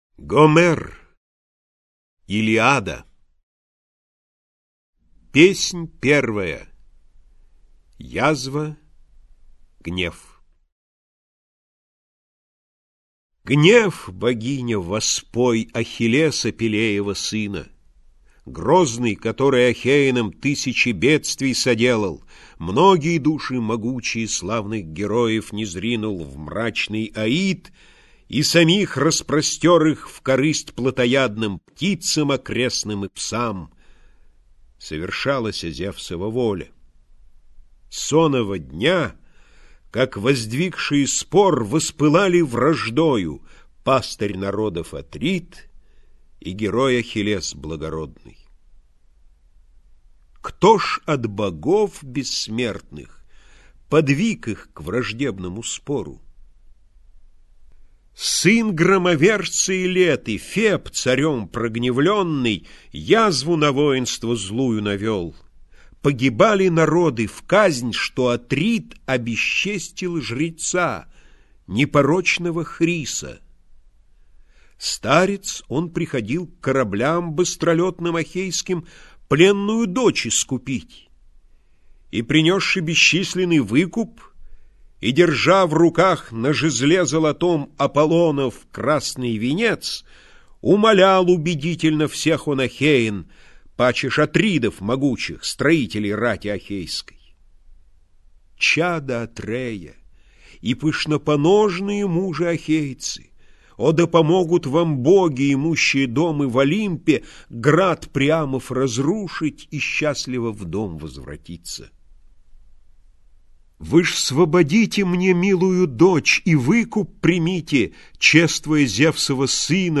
Аудиокнига Илиада - купить, скачать и слушать онлайн | КнигоПоиск